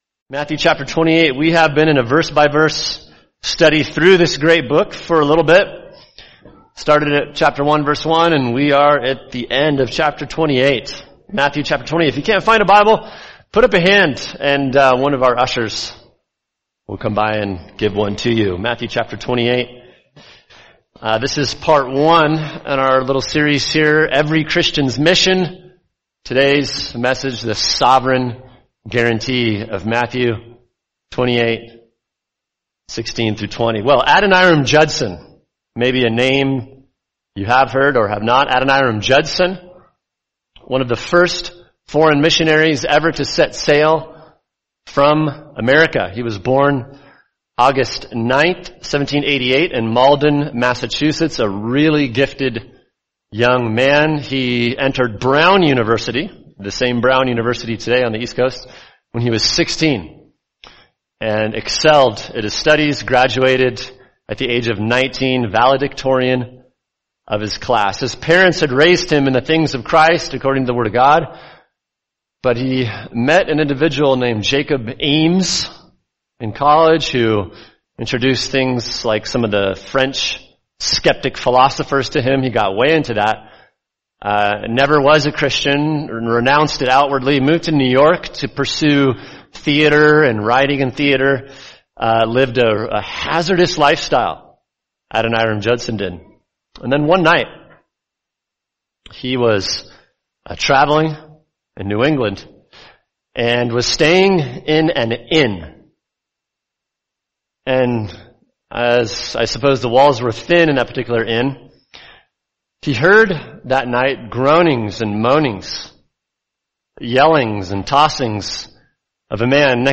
[sermon] Matthew 28:16-20 Every Christian’s Mission – Part 1: The Sovereign Guarantee | Cornerstone Church - Jackson Hole